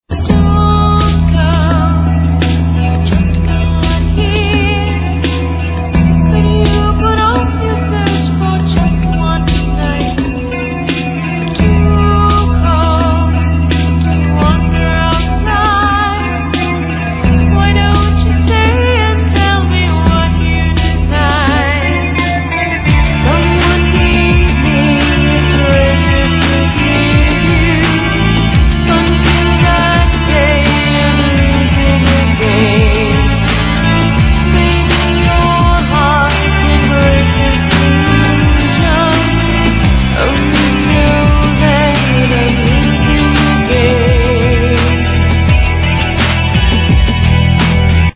Vocals
Violin
Guitars, Bass, Programming
Drums